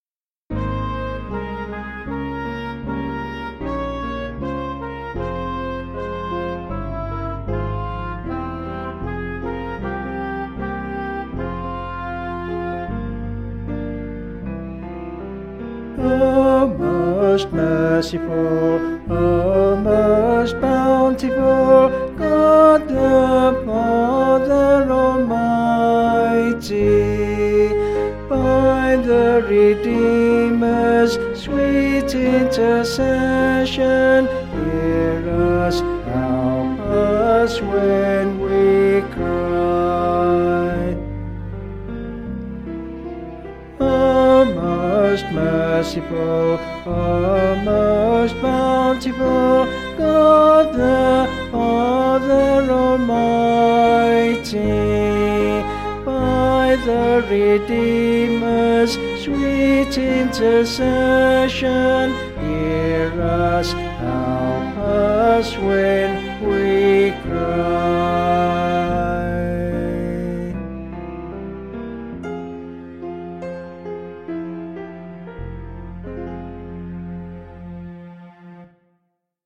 Vocals and Instrumental   264.4kb Sung Lyrics